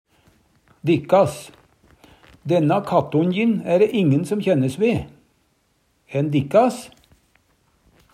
DIALEKTORD PÅ NORMERT NORSK dikkas dykkar, - eigedomspronomen 3.person fleirtal Eksempel på bruk Denna kattonnjin, æ ræ ingen som kjænnes ve.